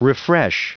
Prononciation du mot refresh en anglais (fichier audio)
Prononciation du mot : refresh